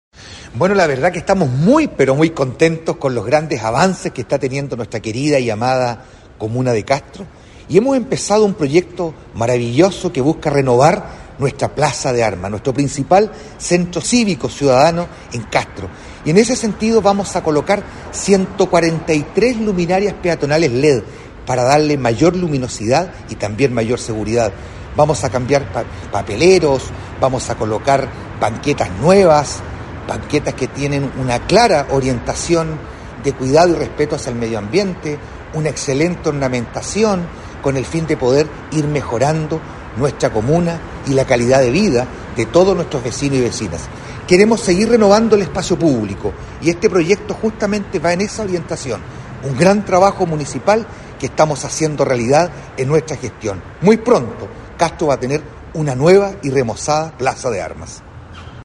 ALCALDE-VERA-PLAZA-DE-ARMAS.mp3